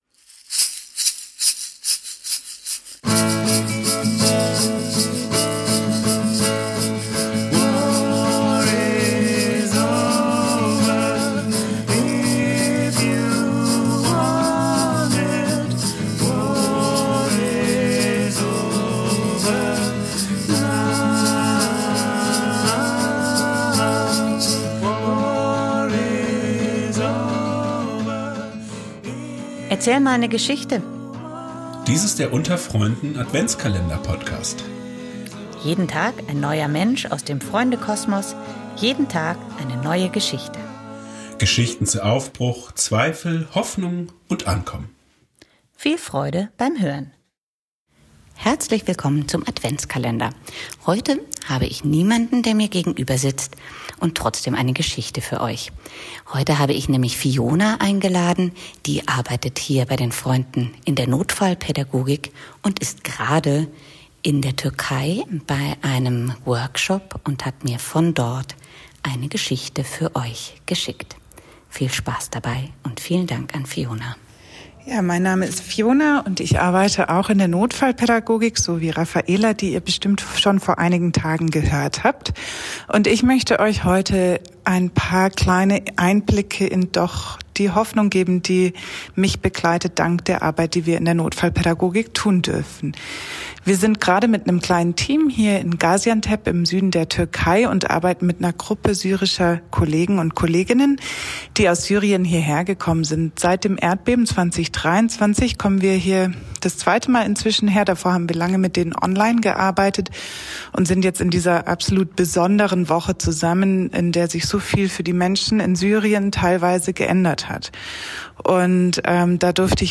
In unserem Adventskalender zum Hören erzählt jeden Tag eine andere